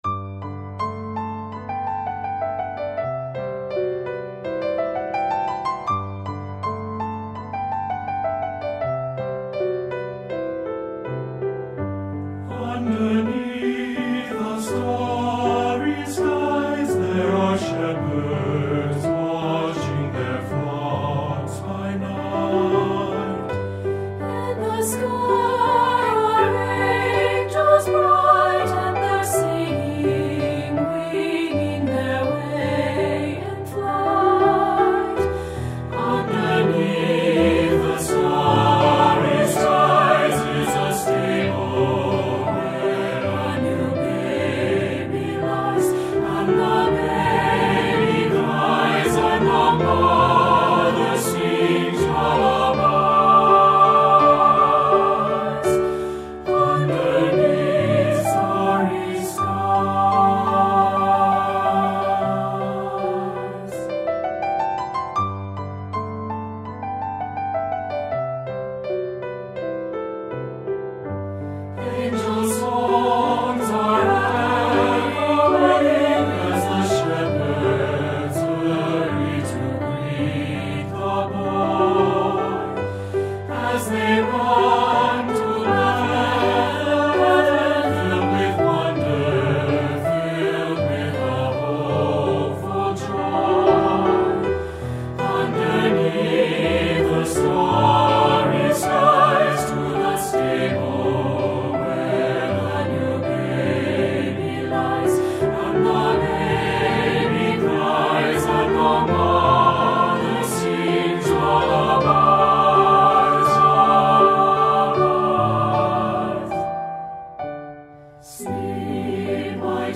Voicing: SSA and Piano